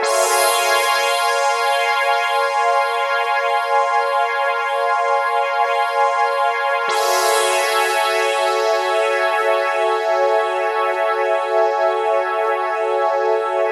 Pad_140_F.wav